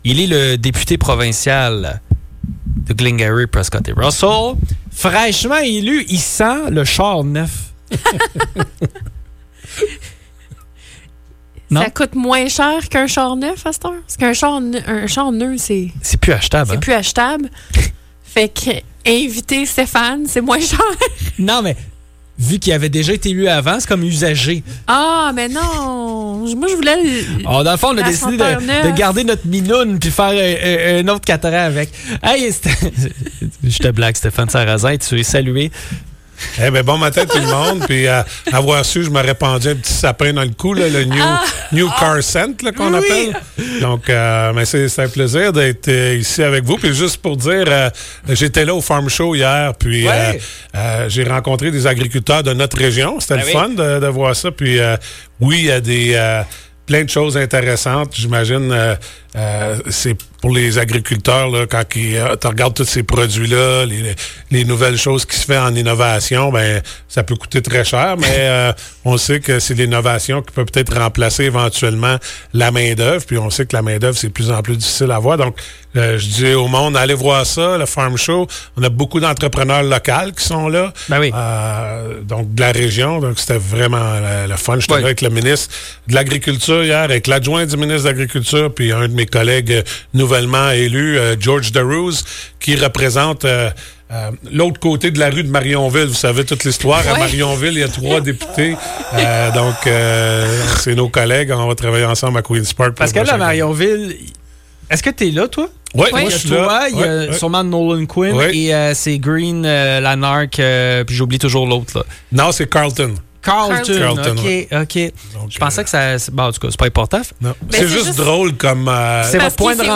Le député provincial de Glengarry-Prescott-Russell, Stéphane Sarrazin, est venu passer une heure avec nous en studio. Nous avons discuté notamment de son mandat renouvelé et des tarifs douaniers.